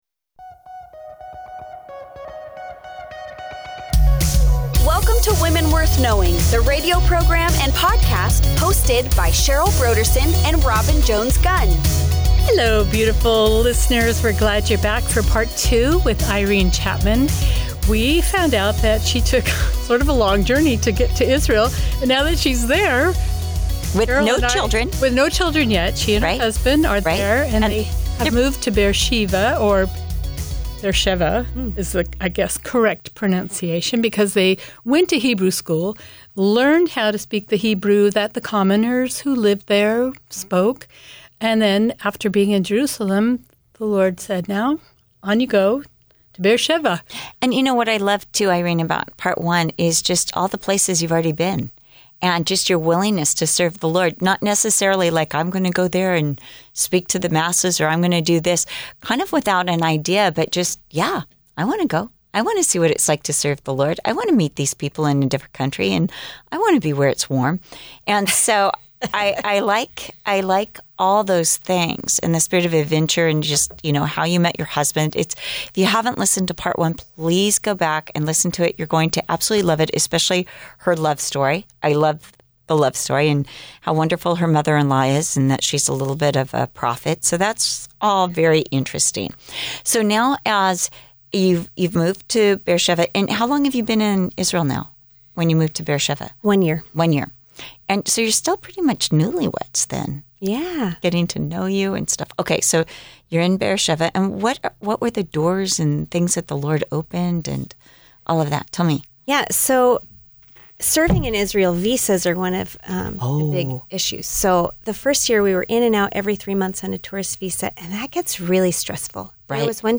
Join us each week for a lively conversation